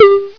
alarmbeep.ogg